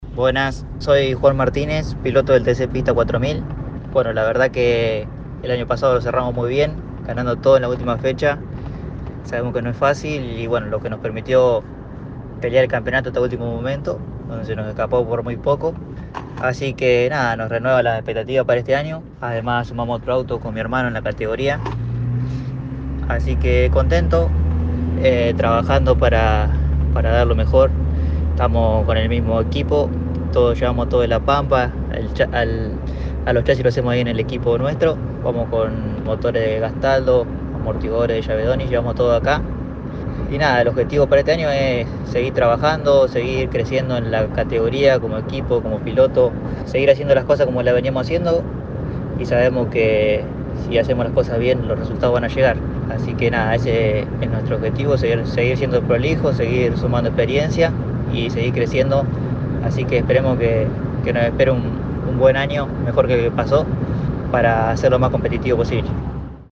Declaraciones del piloto pampeano: